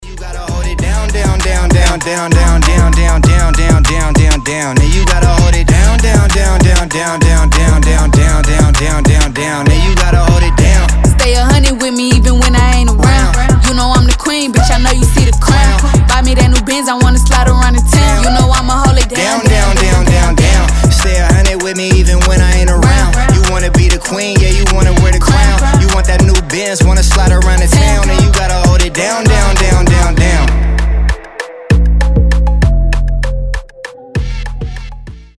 • Качество: 320, Stereo
ритмичные
Хип-хоп
дуэт
качающие